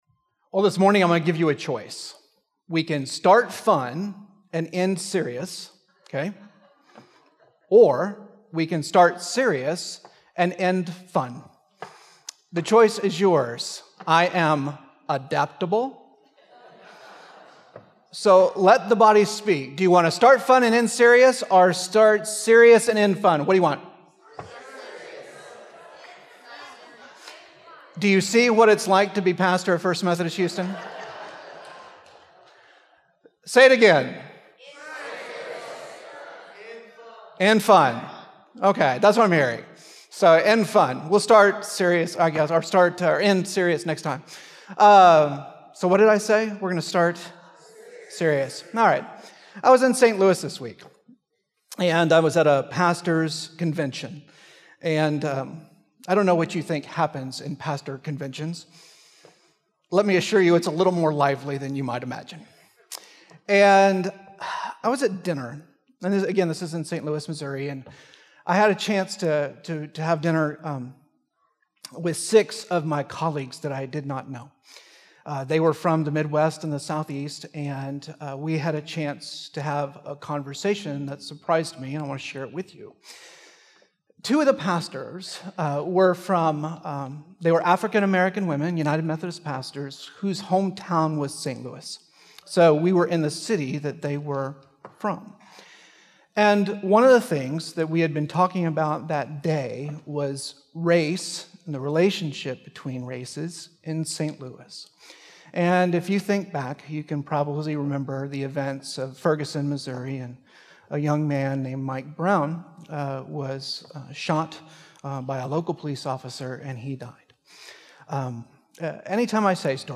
Service Type: Traditional